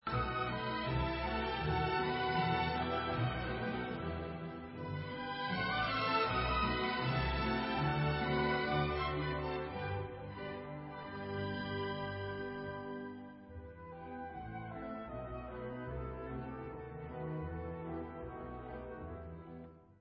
H dur (Molto vivace) /Odzemek